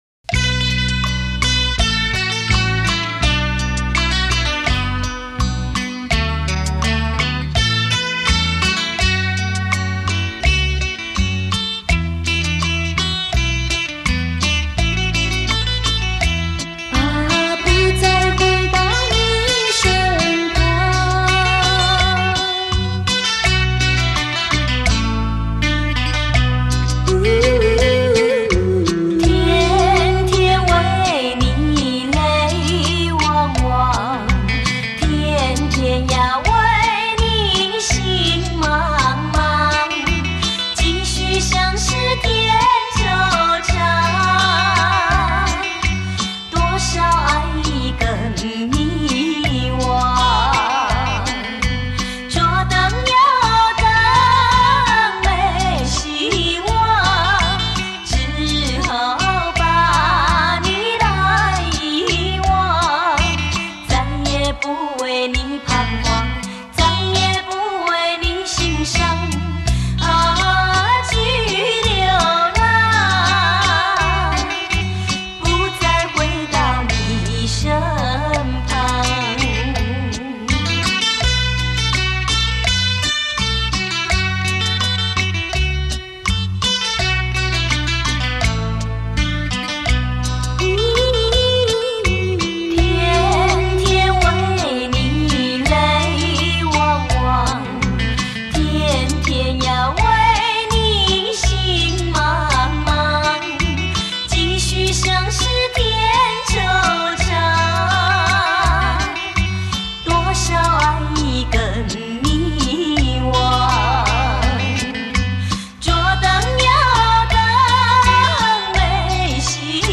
我认为音质一般。